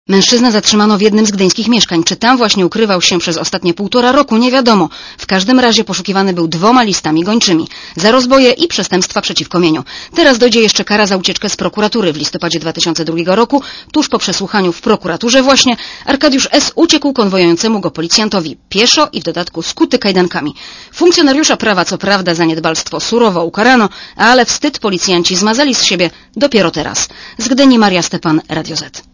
Posłuchaj relacji reporterki Radia Zet (114 KB)